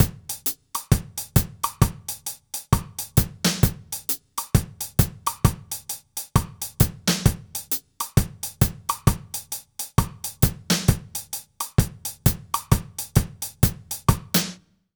British REGGAE Loop 132BPM - 1.wav